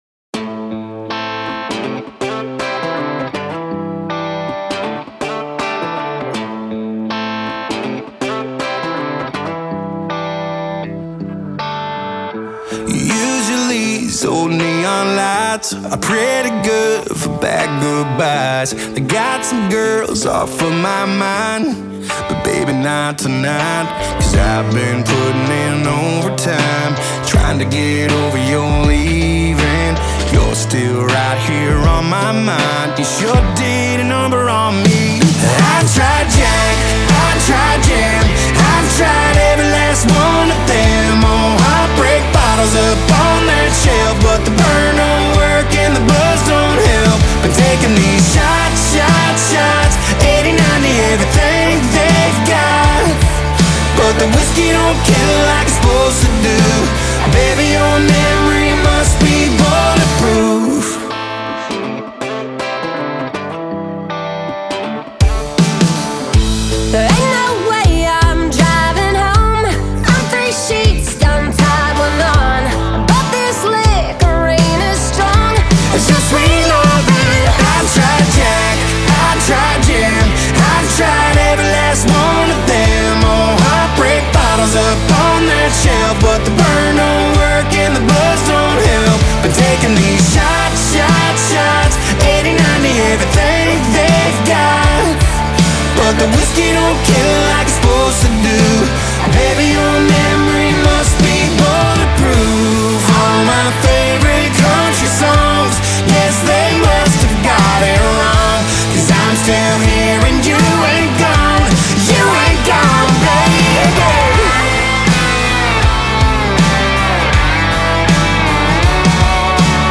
دانلود آهنگ سبک کانتری